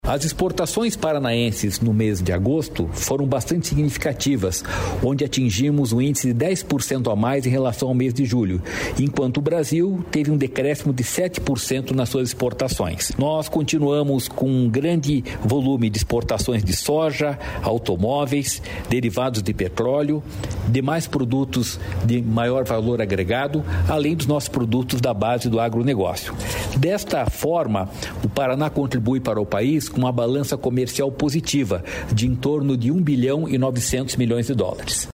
Sonora do diretor-presidente do Ipardes, Jorge Callado, sobre as exportações do Estado